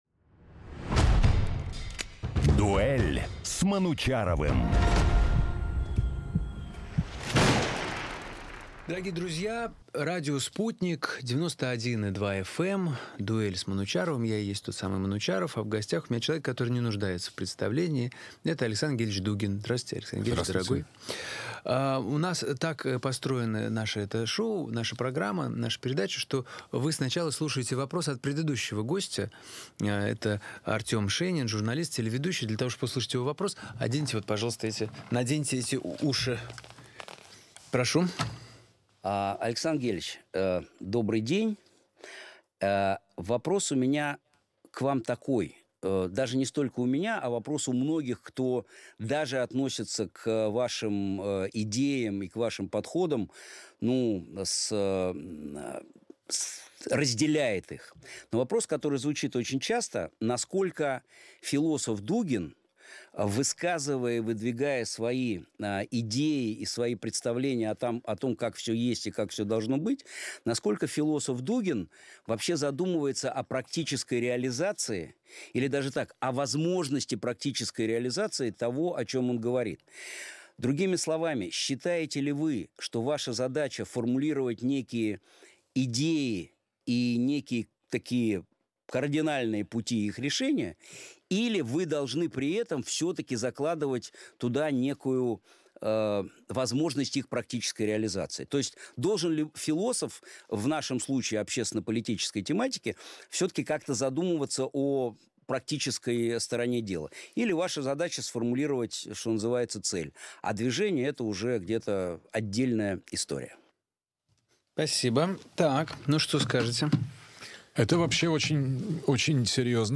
Новый выпуск программы Вячеслава "Манучи" на радио Sputnik. Гость – философ Александр Дугин.